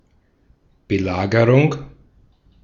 Ääntäminen
Ääntäminen US : IPA : [sidʒ]